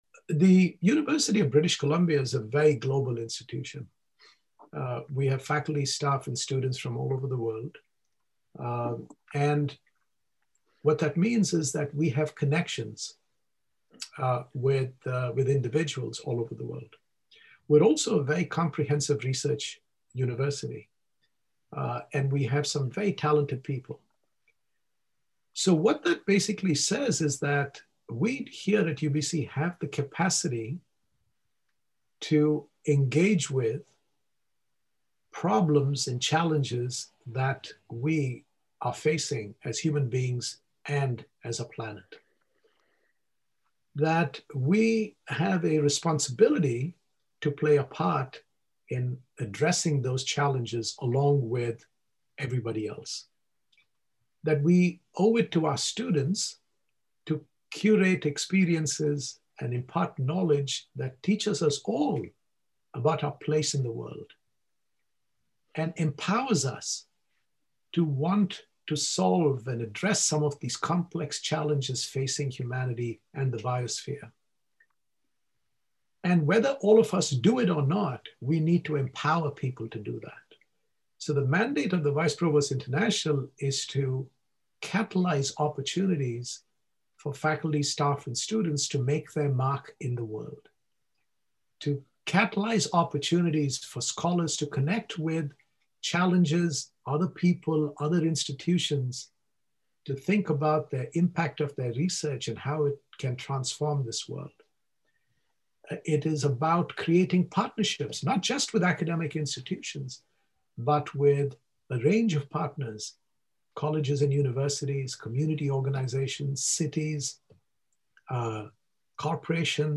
Listen to the full interview with the UBC School of Biomedical Engineering on the SBME Interfaces program.